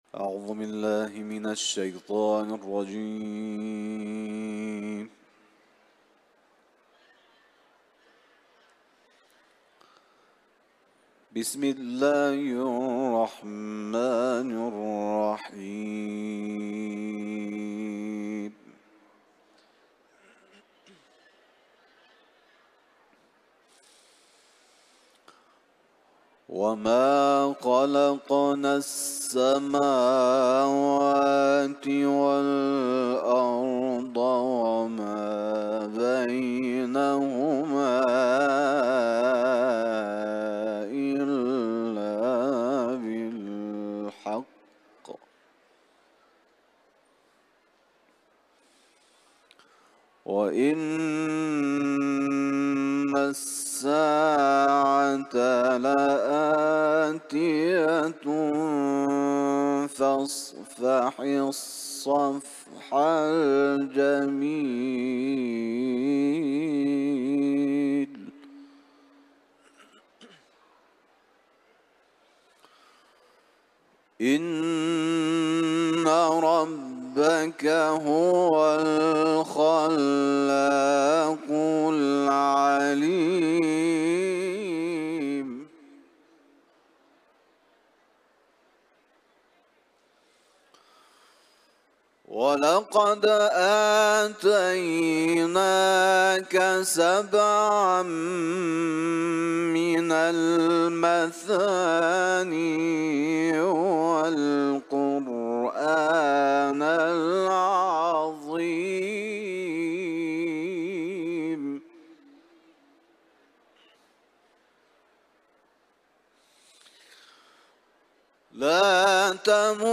Hicr suresinden ayetler tilavet etti.
Etiketler: İranlı kâri ، Kuran tivaleti ، kıraat